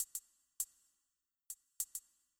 Для «хэтов» я выбрал рисунок длиной в один такт: